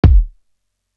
Simmons Kick.wav